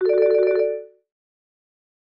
J29_ringtone.wav